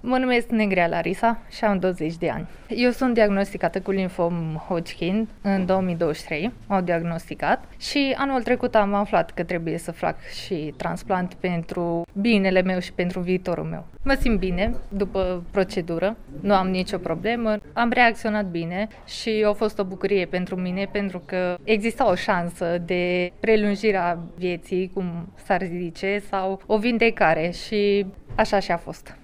Printre persoanele diagnosticate cu acest tip de cancer se afla si o tanara care a trecut deja prin procedura de transplant medular: